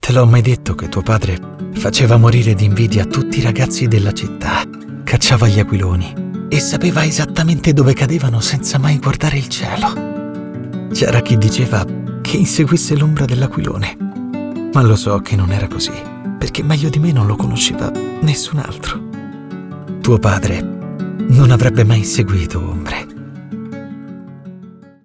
Commercieel, Zacht, Zakelijk, Speels, Veelzijdig